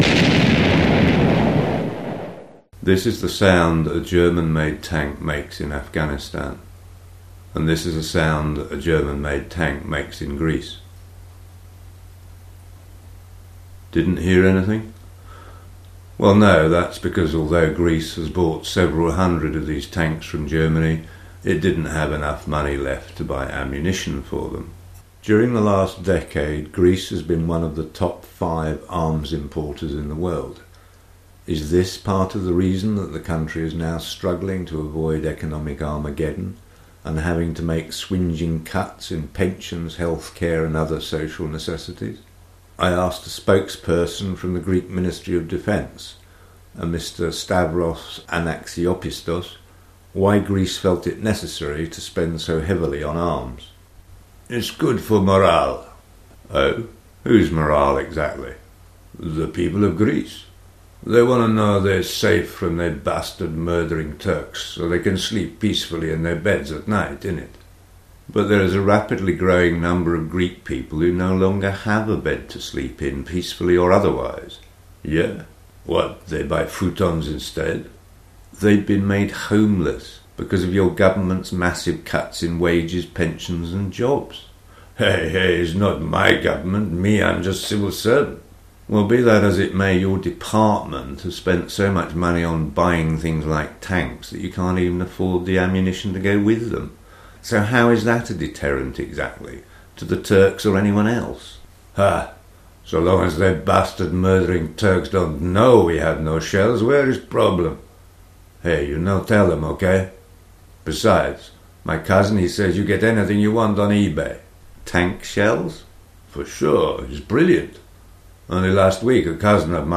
A bit of spoof investigative journalism into why Greece spends enormous amounts of money on buying weapons whilst simultaneously making massive cuts in jobs, wages, pensions and healthcare.
The music used in these podcasts is by kind